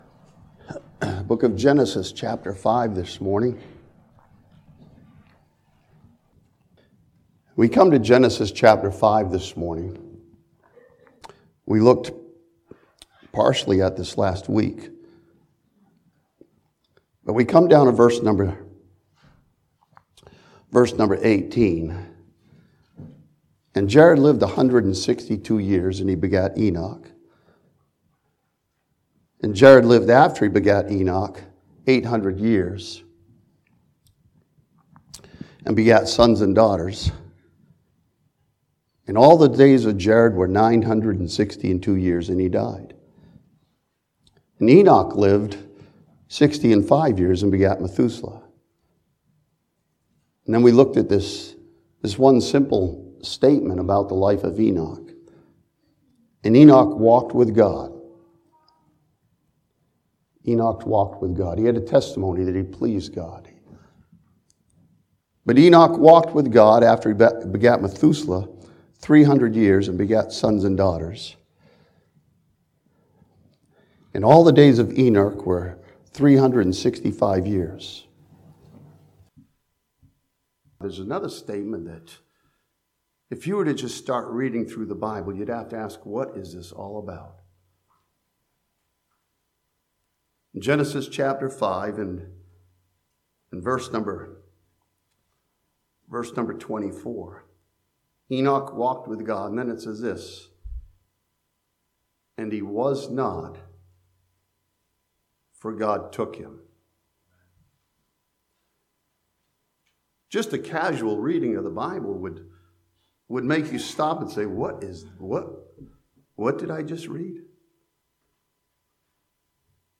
This sermon begins in Genesis 5 with Enoch and continues on to First Thessalonians to understand our assurance and anticipation.